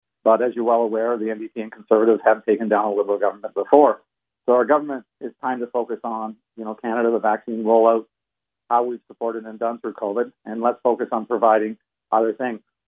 So said the Liberal MP for Bay of Quinte riding Neil Ellis on CJBQ’s Lorne Brooker Show Tuesday.